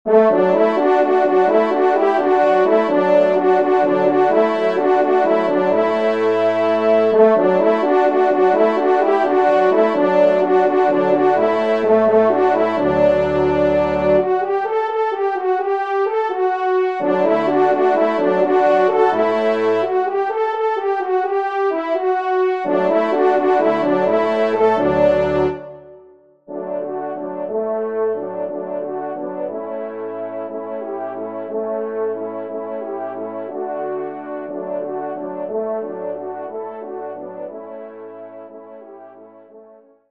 Genre :  Divertissement pour Trompes ou Cors en Ré
ENSEMBLE